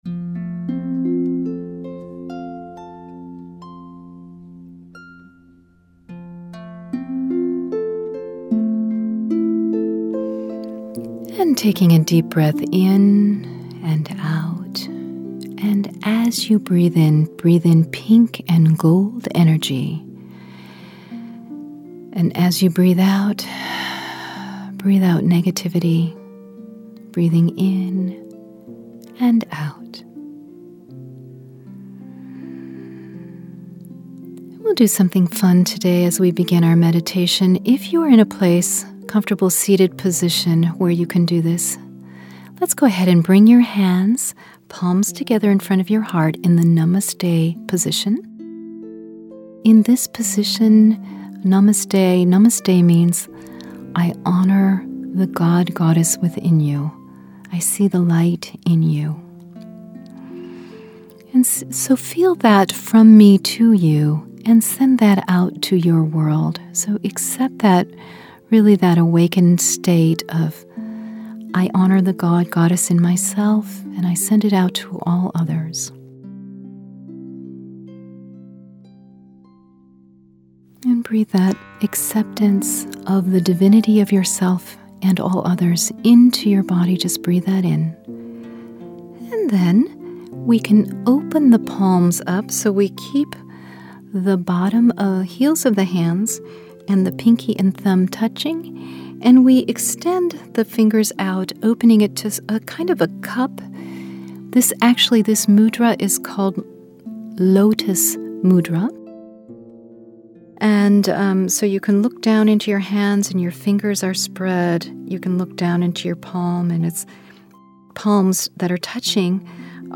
Brilliant Brain Meditation